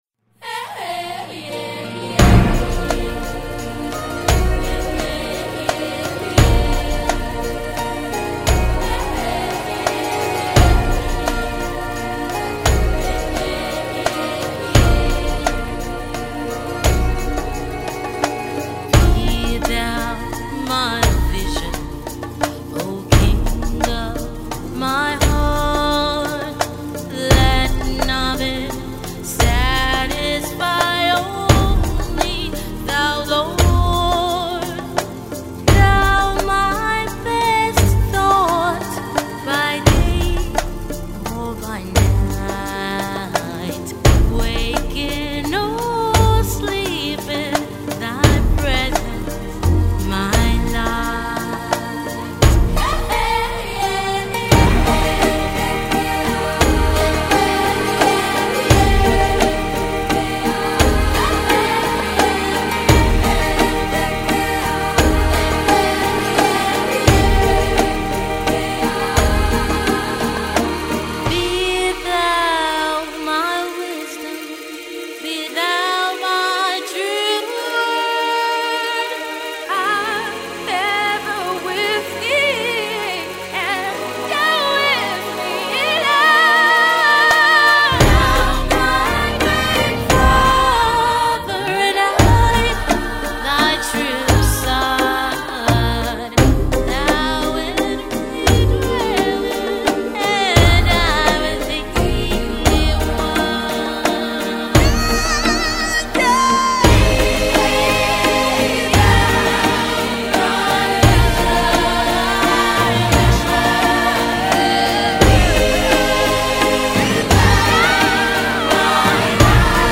a traditional hymn from Ireland.
has a simple and yet haunting beauty.